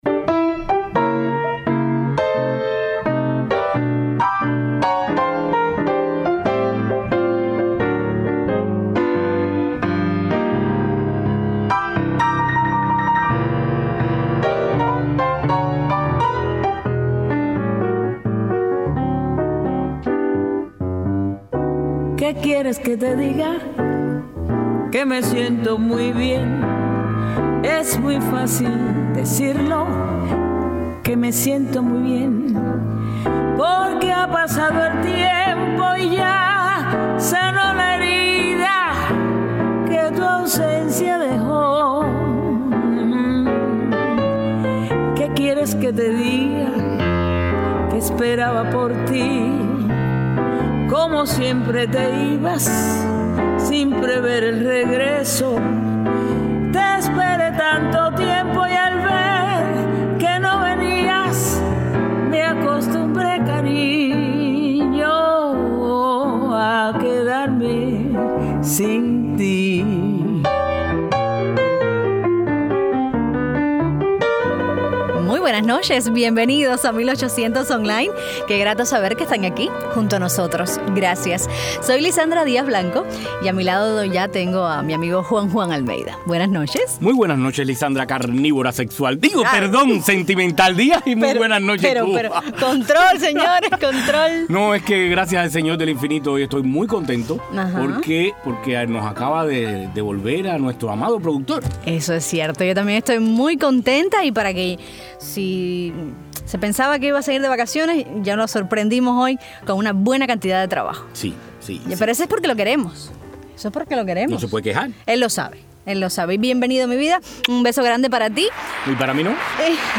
Nos visitan todos los integrantes de la banda de rock Hipnosis, que recién han decidido establecerse en los Estados Unidos. Junto a ellos nos acercamos al mundo del rock en Cuba, nos hablan de sus planes y comparten su música.
Con estos muchachos, 1800 Online se viste de negro y metálico.